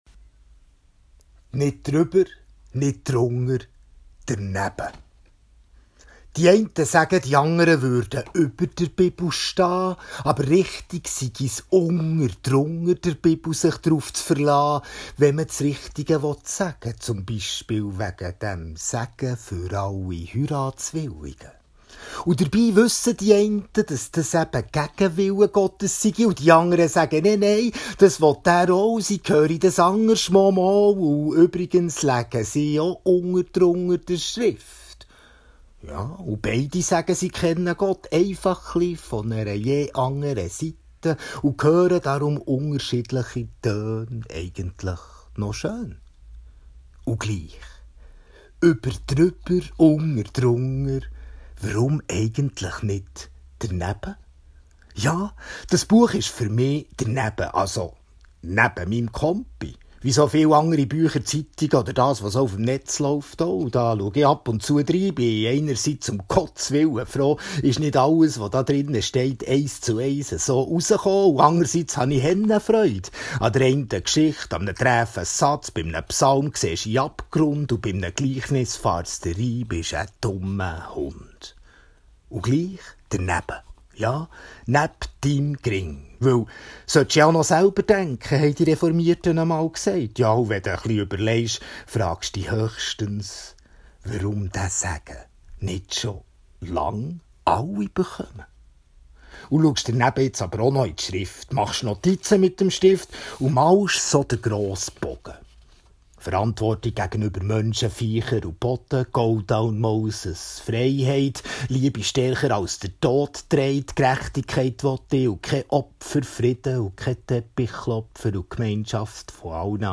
Audiobeitrag (3:30)
Mit viel Humor treffend gesagt!
Ich haut Bärndütsch u Bärndütsch ich haut gäng öppis schöns…